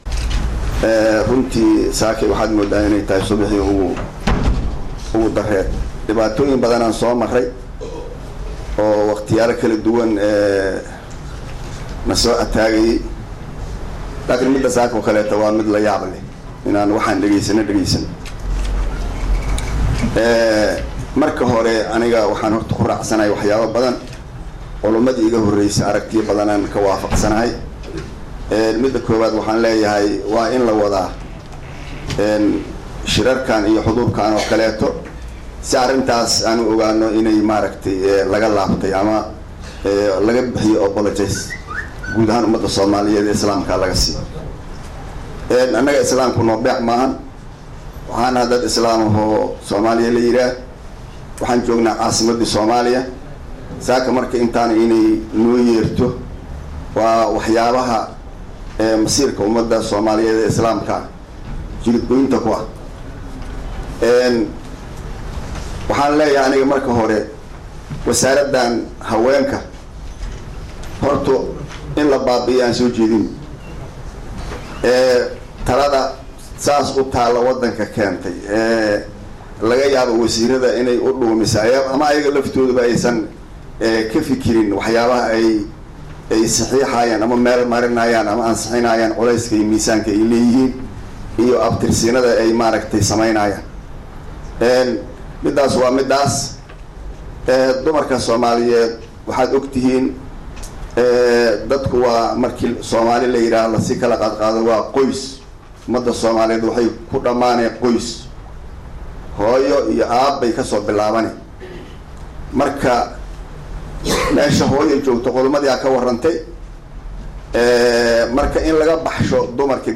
isagoo ka hadlaya kullan ay soo Abaabushay Kooxda DAMUL JADIID